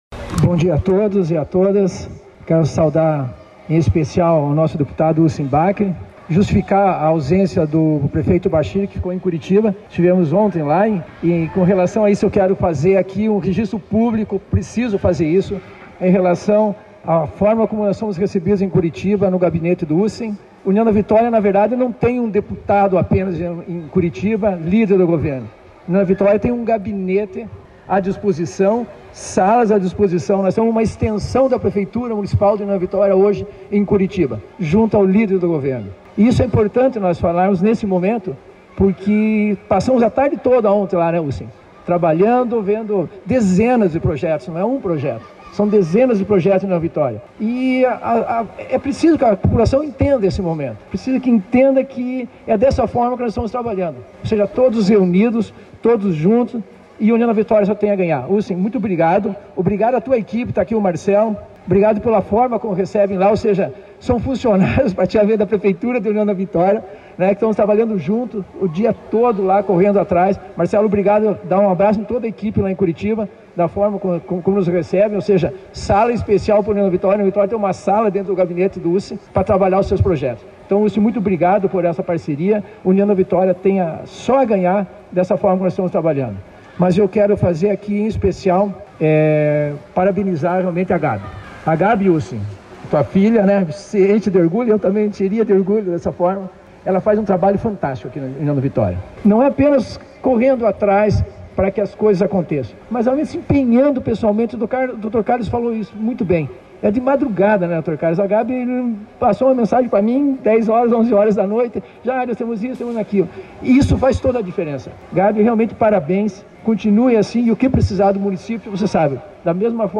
O Vice-prefeito de União da Vitória, Jairo Clivatti, agradeceu ao trabalho da Secretaria Regional da Justiça, Família e Trabalho, e justificou a ausência do prefeito, Bachir Abbas, que ficou cumprindo agenda em Curitiba.